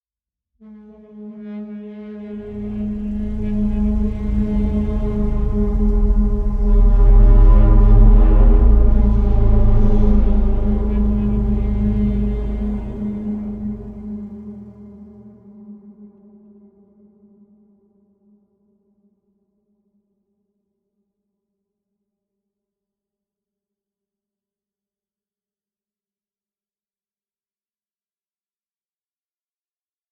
CINEMATIC - BRAAAM 5 - Eerie Metallic Horns Tonal Swells Distant Screeching - Call of the Dark B.wav